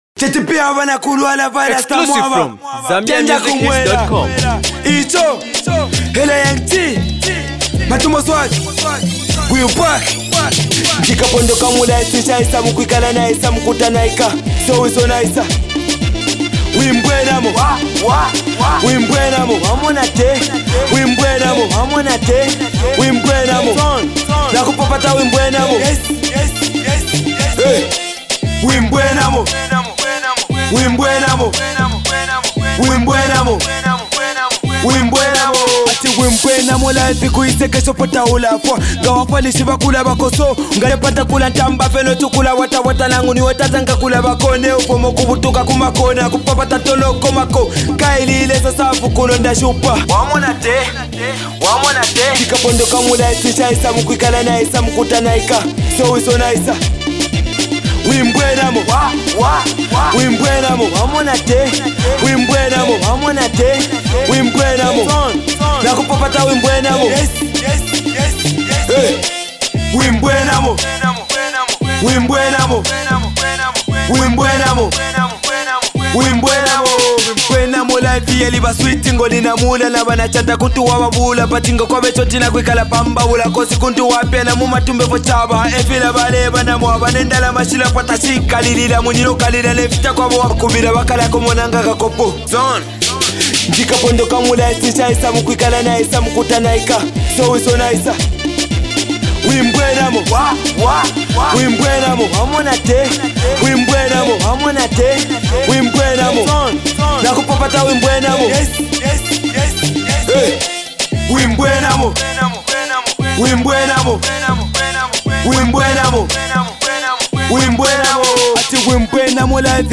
a fast Uprising Rapper